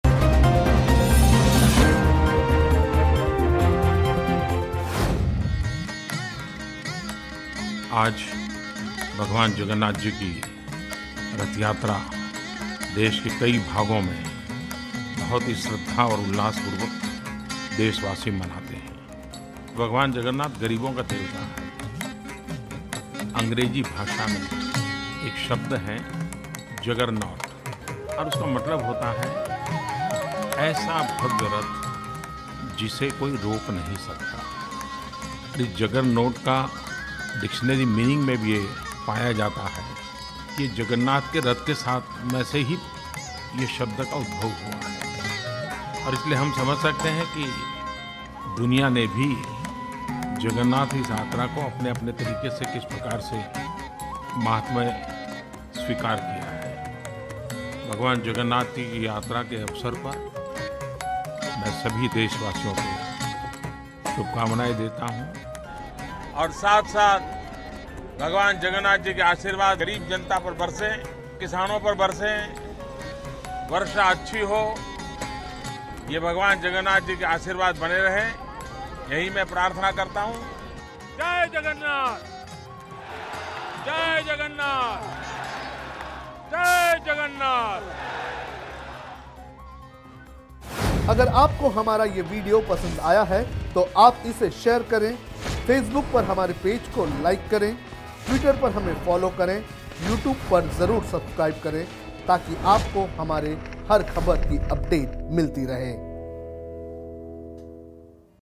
न्यूज़ रिपोर्ट - News Report Hindi / Jagannath Rath Yatra 2018 : पूरी से लेकर अहमदाबाद तक सुरक्षा के कड़े इंतज़ाम